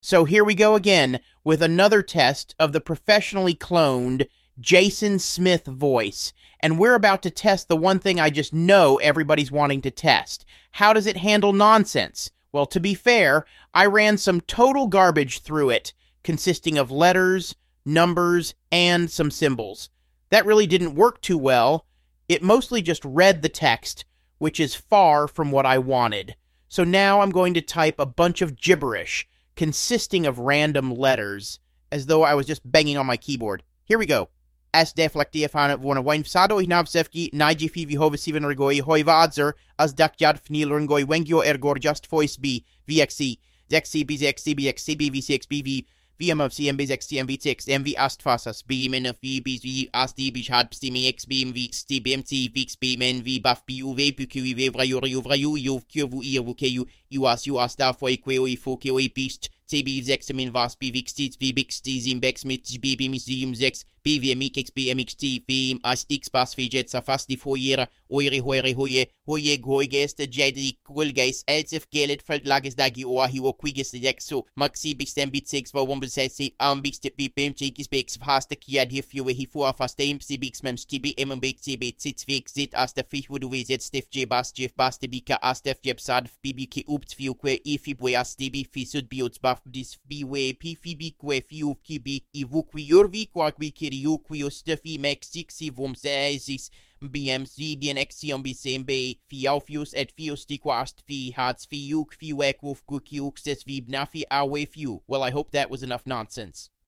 Here I have my professionally cloned voice read some total nonsense.